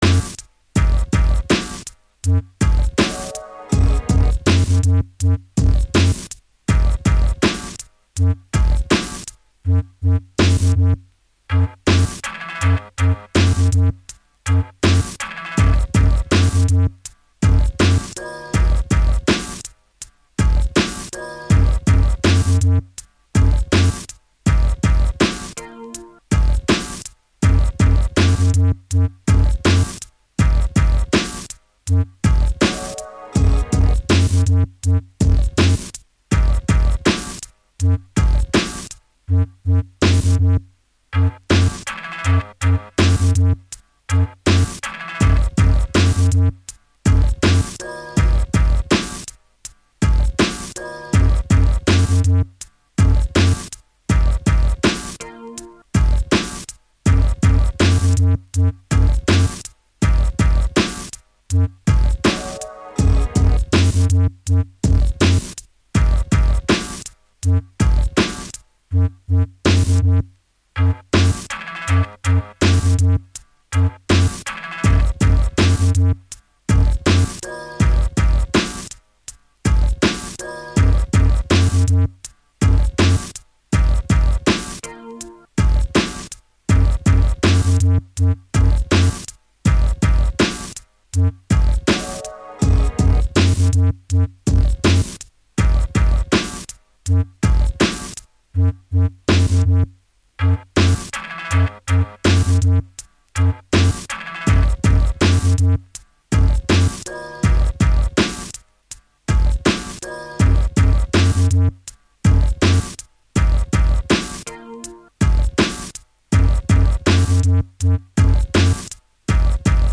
Genre: Hip Hop
Style: DJ Battle Tool, Cut-up/DJ, Turntablism